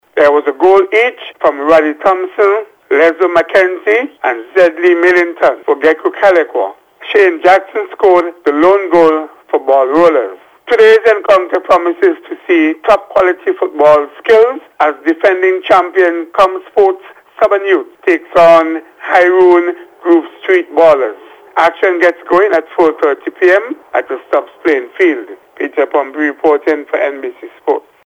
With a report